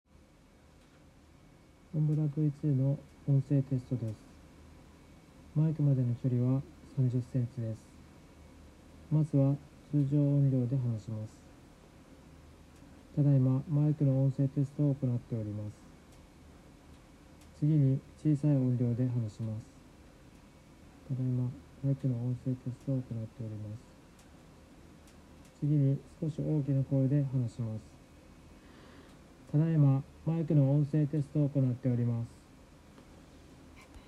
以下の「音声テストで読み上げる内容」に記載の通り、顔から30cmに音声録音用のマイクを設置してテストを行いました。
■検証②（OMBRA v2を装着して読み上げる）
この検証結果からもわかるように、OMBRA v2を装着することでかなりの防音効果が出ていることがわかります。
検証②の結果をみて、「確かに防音はできているけど、声がこもって聞こえる。」と感じた方も多いと思います。